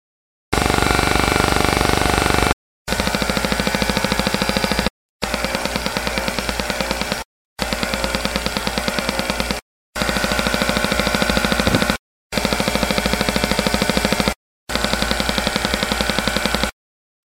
the air passing through the bleed cup and each of the six unsealed leather pouches.